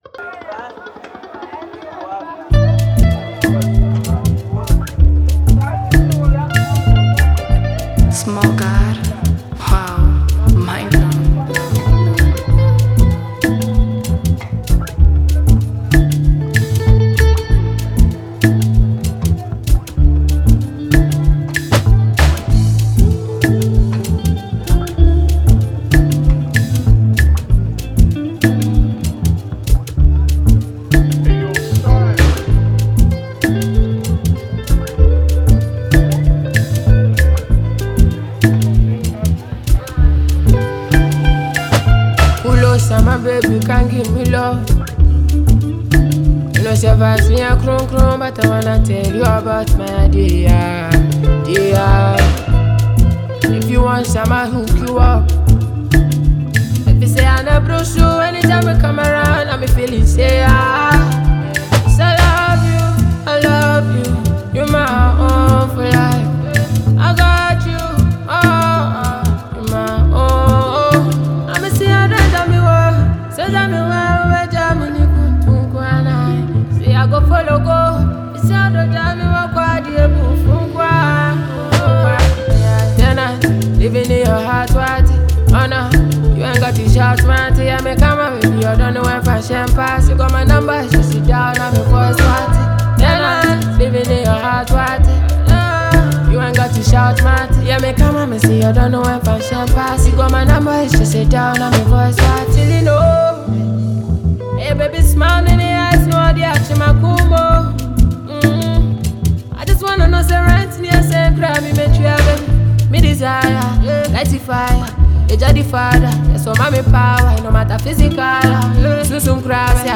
blends afrobeat rhythms with warm, romantic melodies